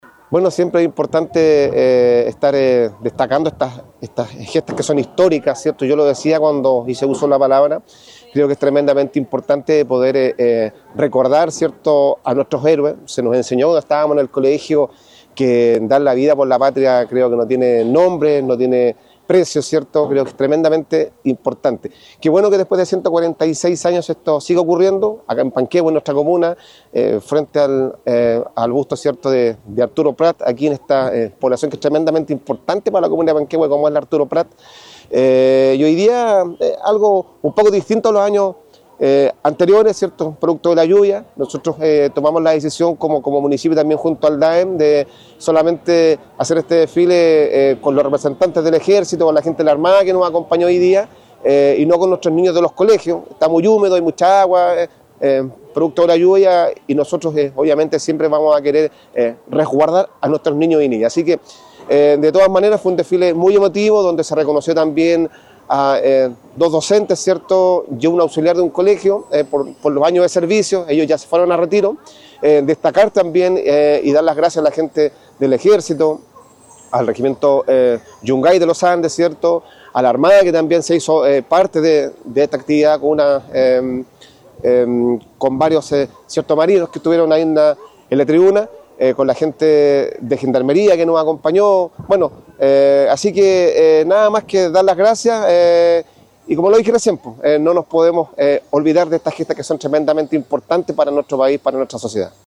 El Alcalde Vergara señaló lo siguiente.
ALCALDE-VERGARA-DESFILE-21-DE-MAYO-PANQUEHUE-.mp3